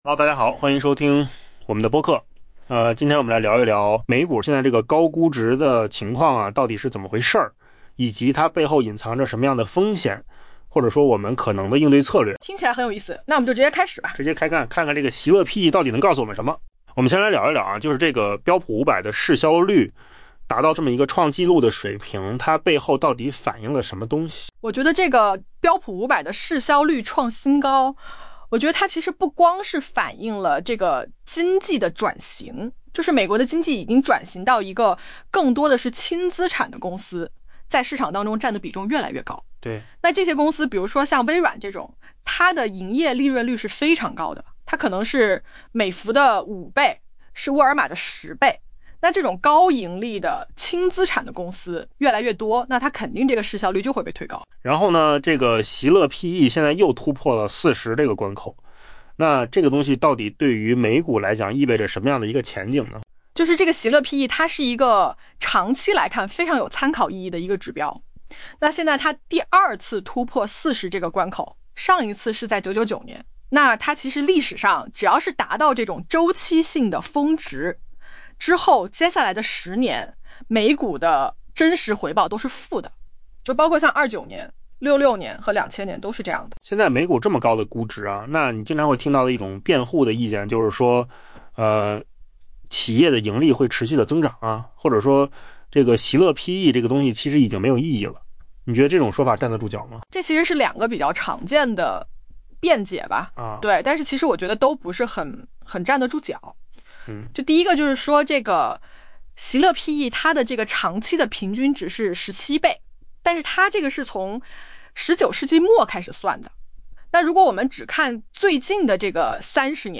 AI 播客：换个方式听新闻 下载 mp3 音频由扣子空间生成 「未知领域」 是如今投资者常听到的说法。